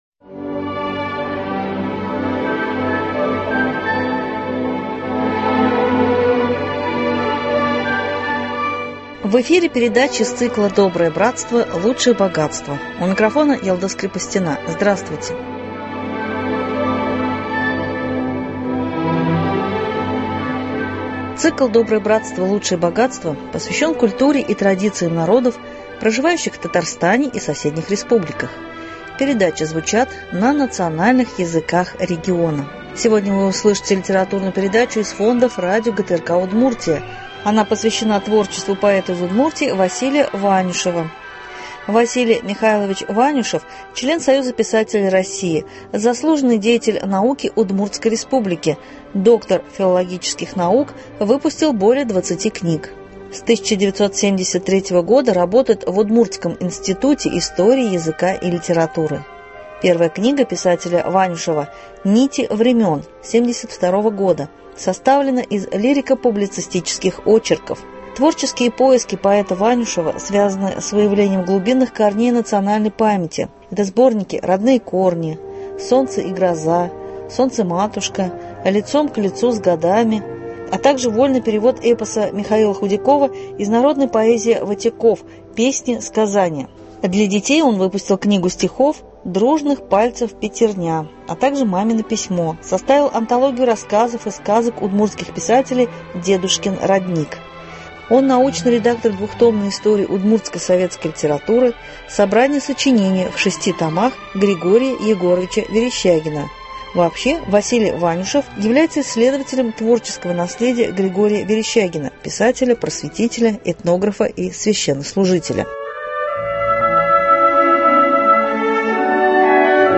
Сегодня вы услышите литературную передачу из фонда радио ГТРК Удмуртия.